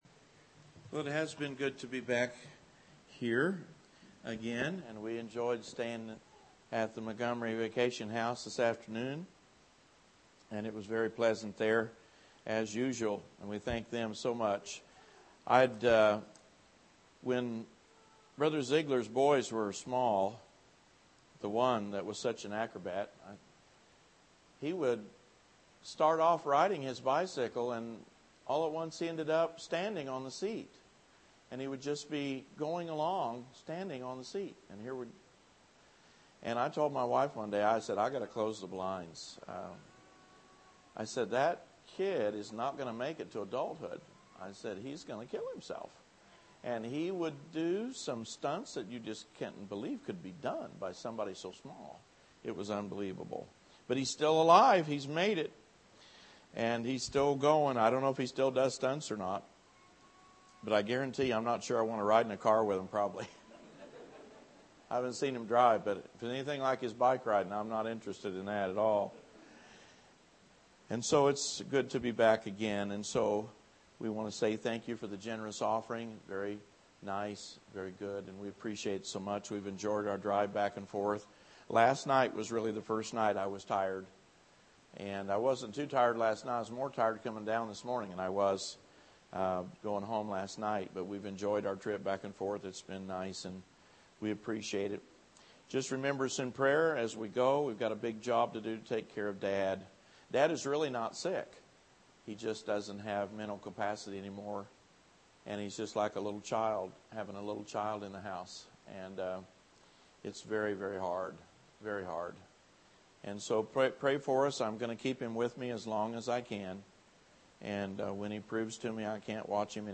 The closing sermon of the 2015 Spring Revival.